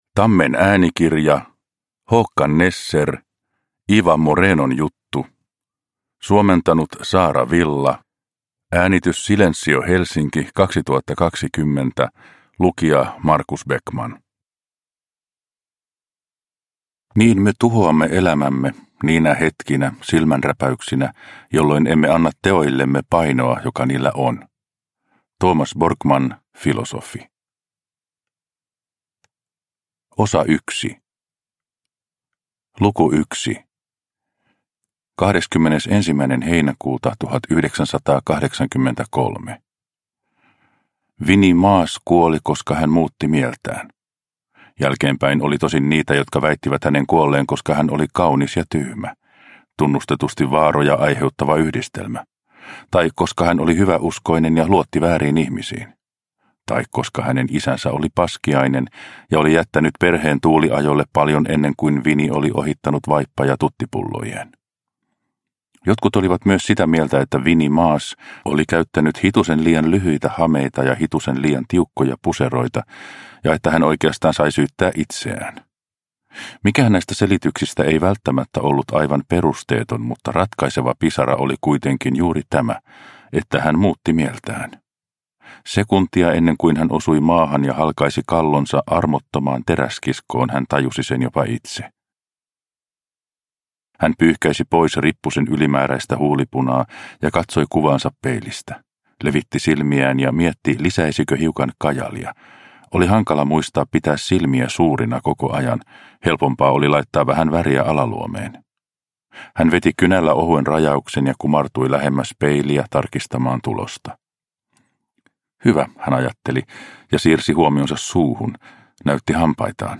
Ewa Morenon juttu – Ljudbok – Laddas ner